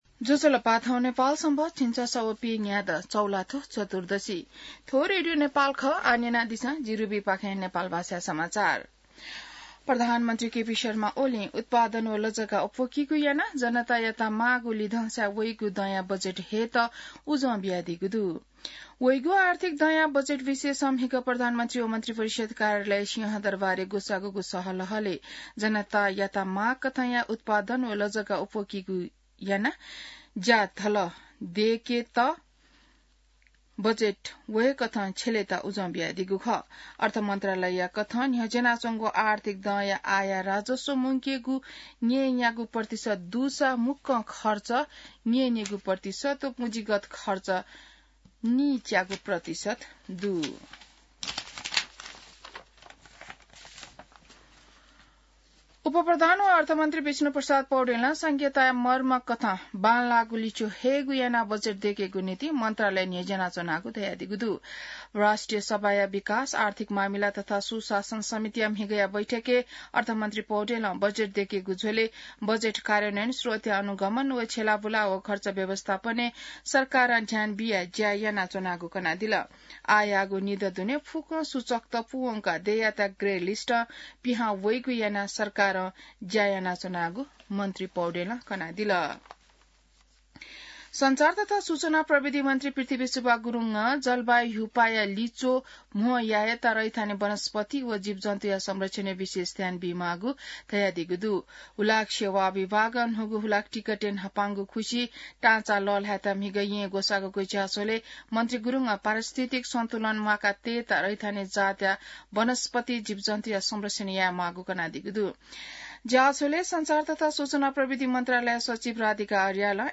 नेपाल भाषामा समाचार : २९ चैत , २०८१